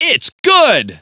itsgood.wav